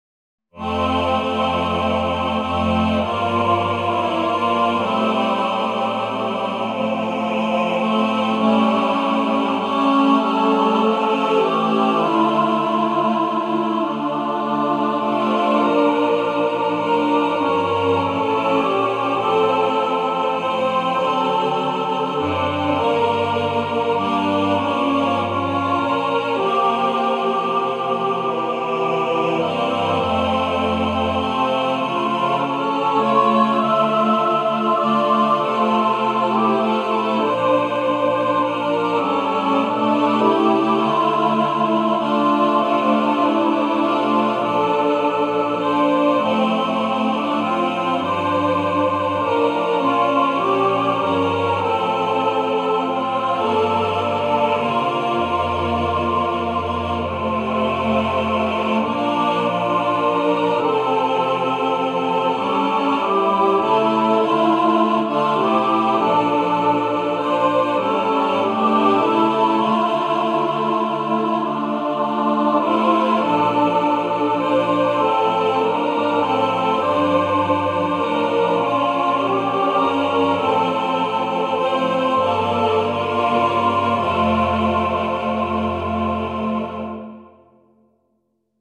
A beautiful, sacred hymn